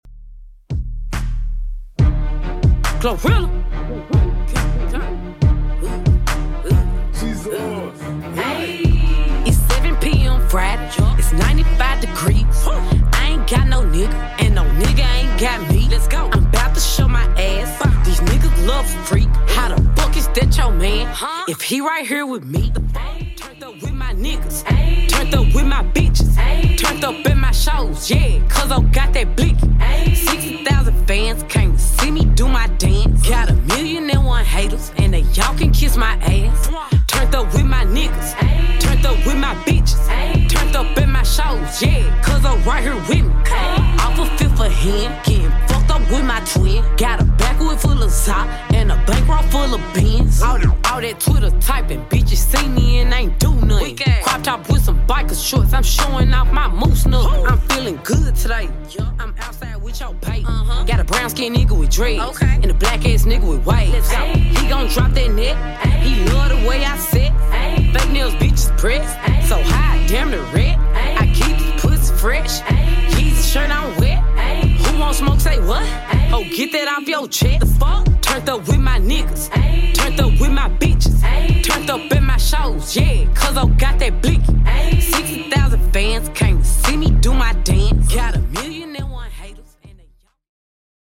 Genre: 80's
BPM: 125